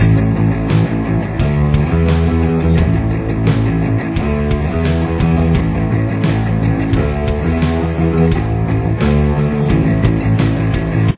Intro/Verse